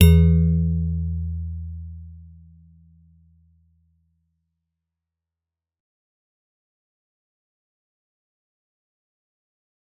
G_Musicbox-E2-f.wav